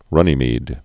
(rŭnē-mēd)